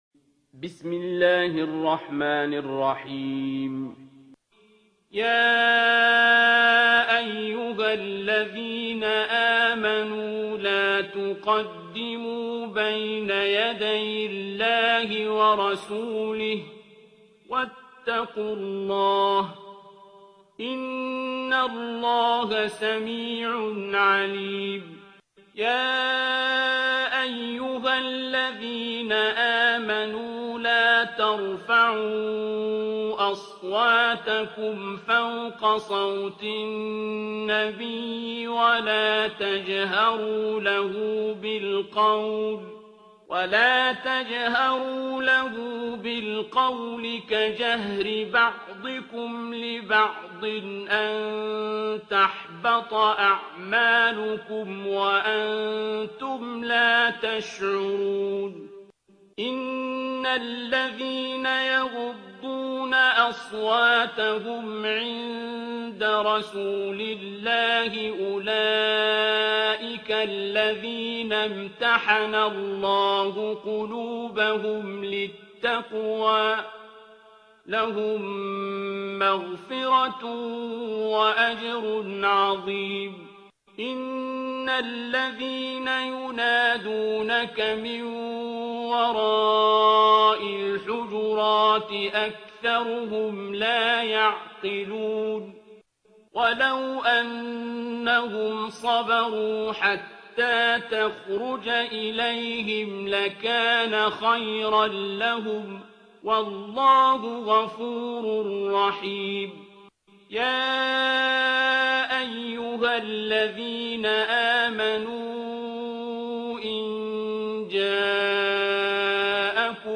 ترتیل سوره حجرات با صدای عبدالباسط عبدالصمد